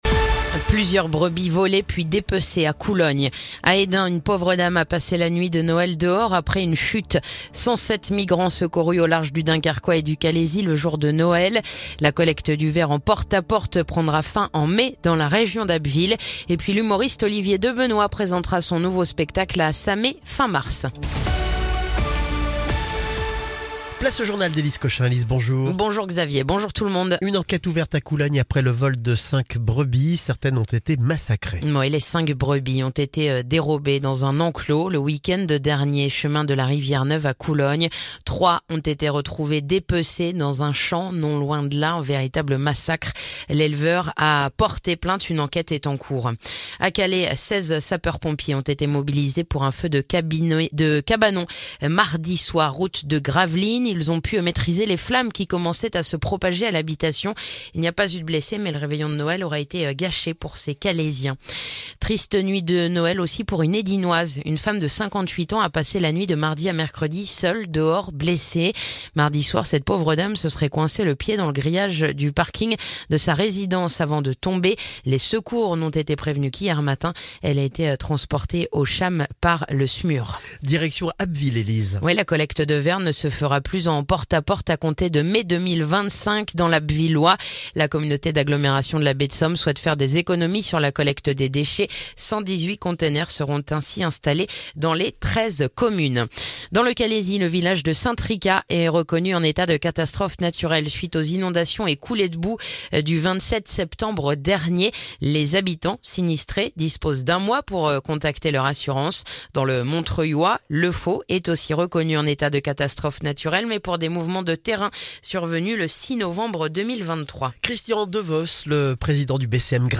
Le journal du jeudi 26 décembre